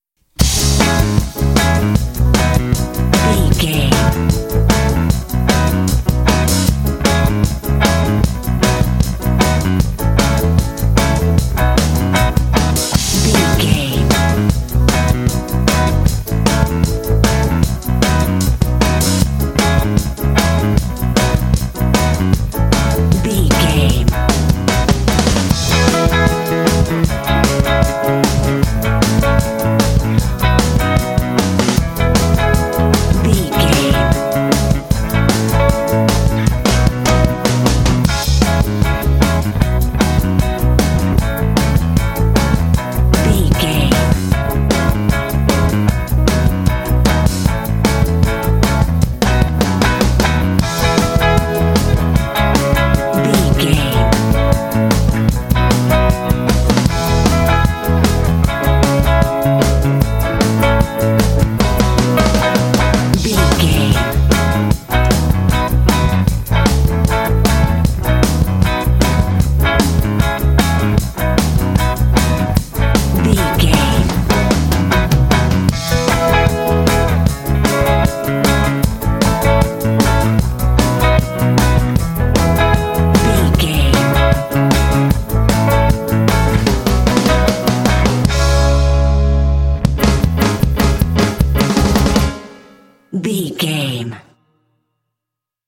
Uplifting
Ionian/Major
funky
happy
bouncy
electric guitar
bass guitar
drums
piano
percussion
upbeat